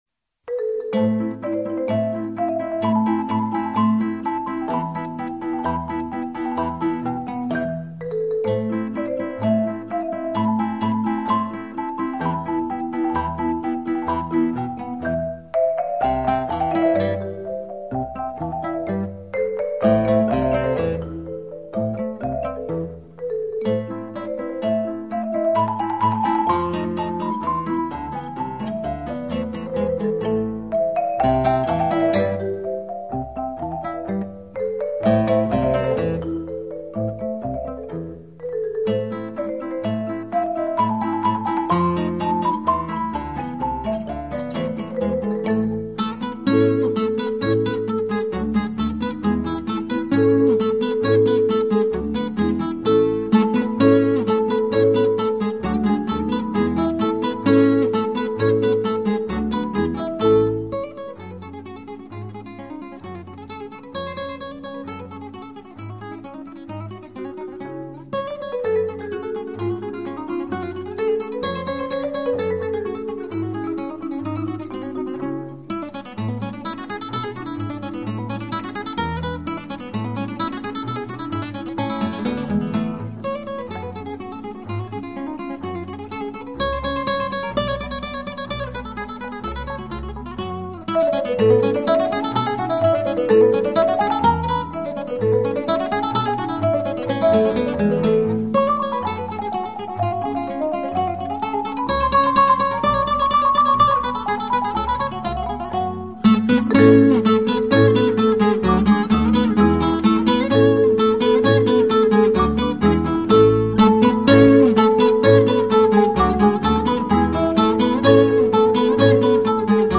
吉他专辑
醇和，柔美华丽的音色，颗粒饱满，光辉璀璨的音质，清晰的层次
丰富的和弦，一切你能想到的和希望的精华都在本辑中展露无遗。